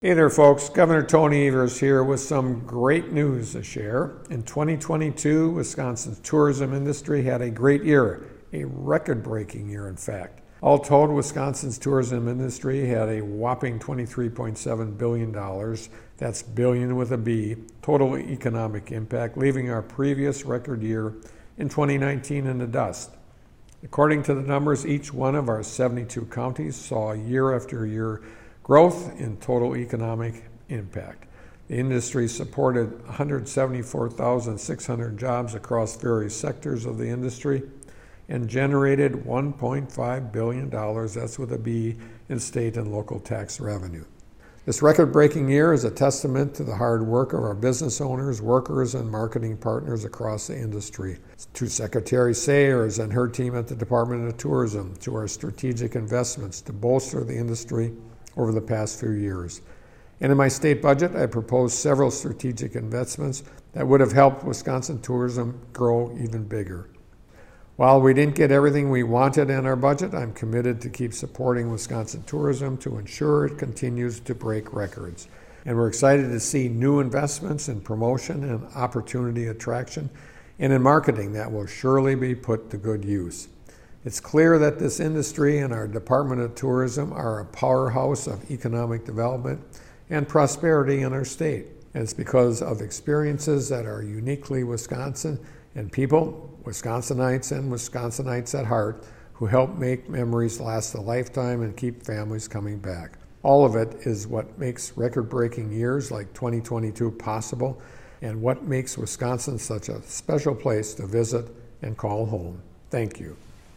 MADISON — Gov. Tony Evers today delivered the Democratic Radio Address celebrating 2022 economic impact data showing Wisconsin’s tourism industry generated $23.7 billion in total economic impact, surpassing the previous record year of $22.2 billion set in 2019.